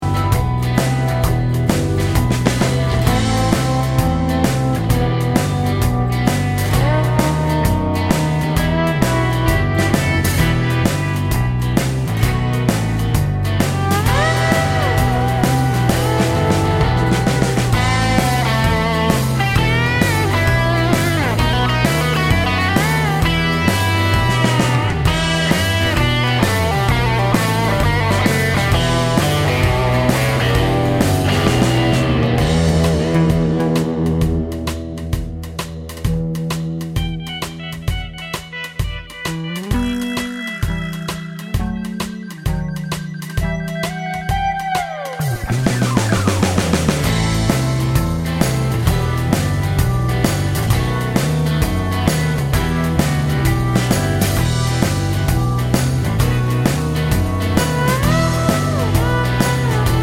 Minus Main Guitars Soundtracks 2:19 Buy £1.50